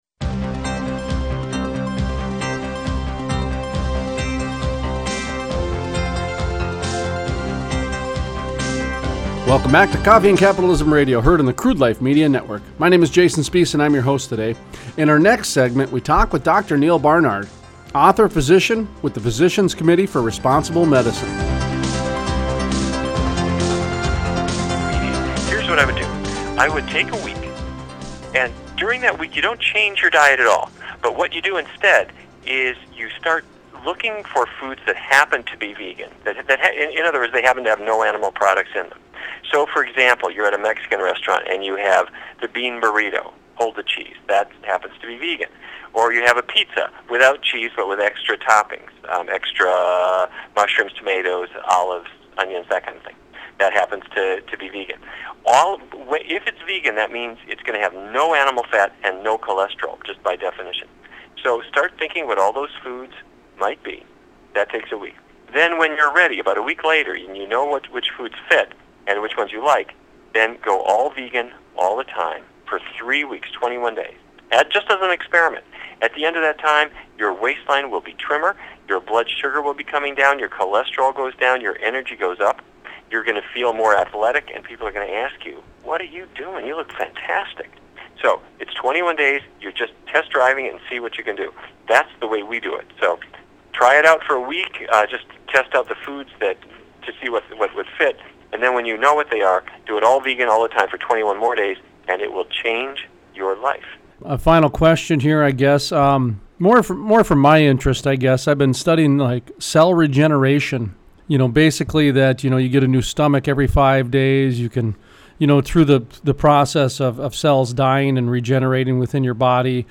Interviews: Neal Barnard, physician, author and dietician, Physicians Committee for Responsible Medicine Talks about the business of vegan and other comments on health and diets.